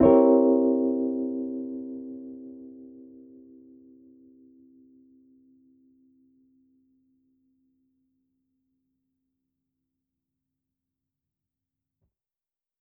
JK_ElPiano3_Chord-C7b9.wav